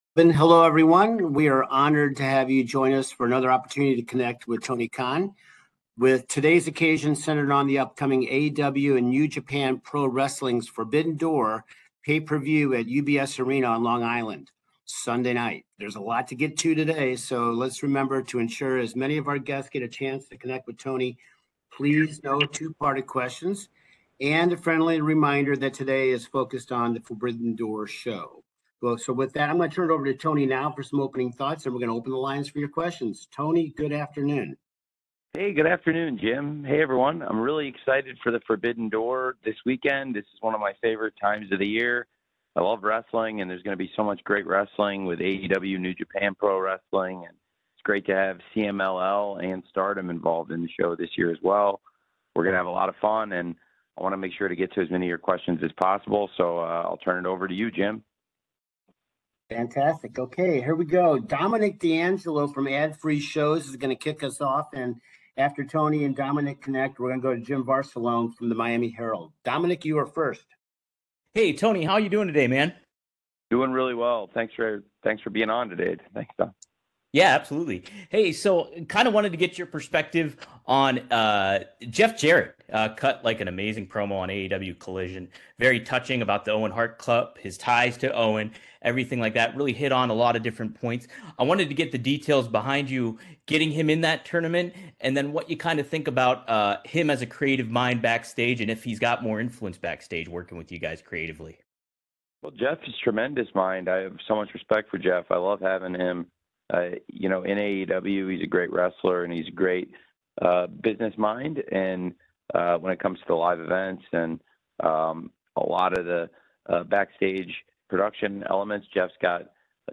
Tony Khan speaks with the media ahead of AEW X NJPW Forbidden 2024 taking place on Sunday, June 30, 2024 at the USB Arena in Elmont, NY.